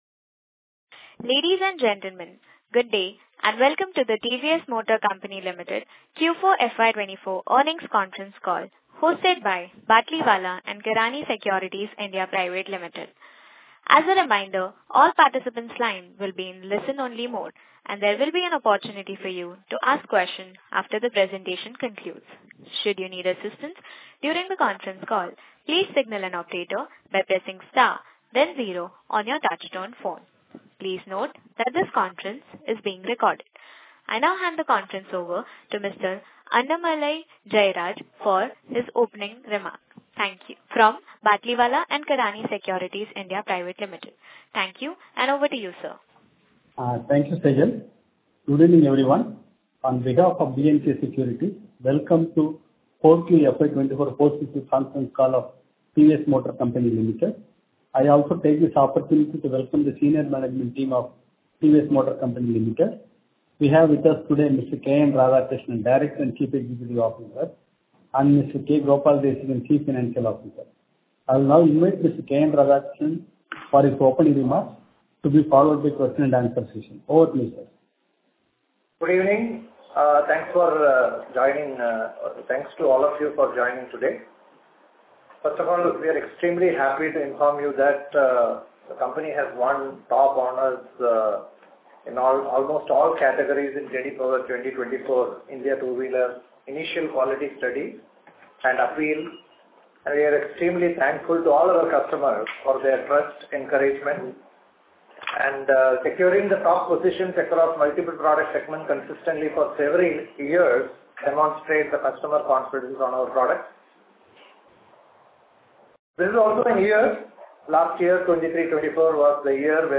Investor Call Recording - 8 May 2024.mp3